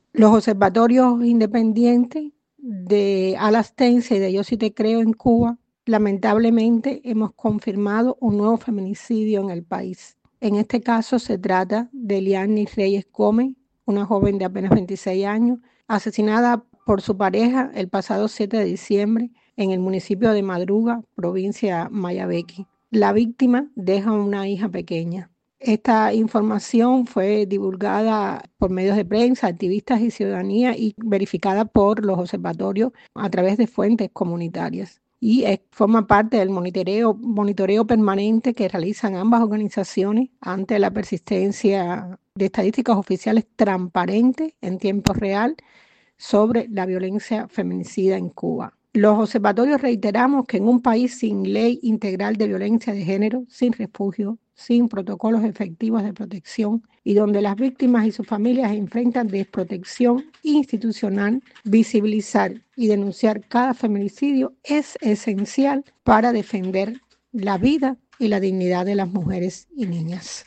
Feminicidios en Cuba: Testimonio